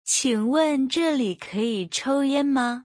日本人にとって「zhè」の発音は苦手なため、喉の奥から発音を意識するようにしてください。
Qǐngwèn zhèlǐ kěyǐ chōuyān ma?【ピンイン】